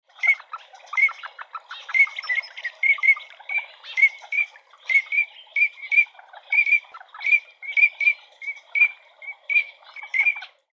コガモ｜日本の鳥百科｜サントリーの愛鳥活動
「日本の鳥百科」コガモの紹介です（鳴き声あり）。